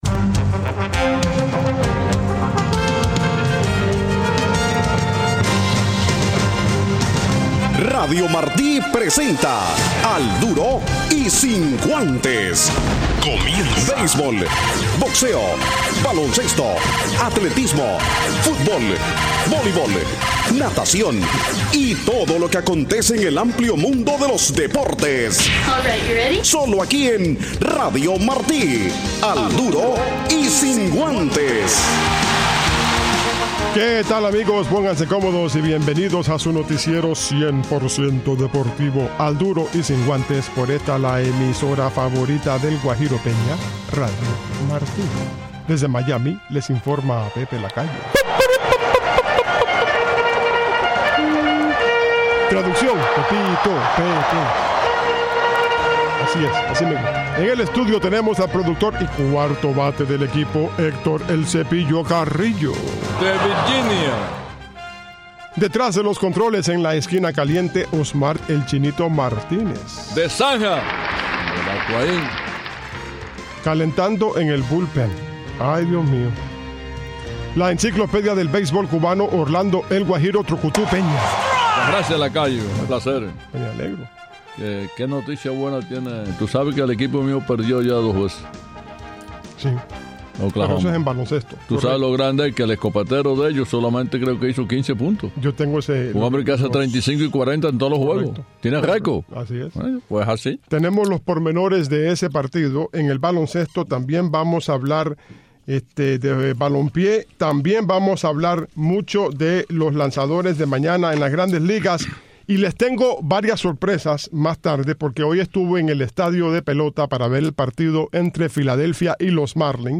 Al Duro del jueves con entrevistas de Marcell Ozuna, Adeiny Hechavarria y Felo Ramirez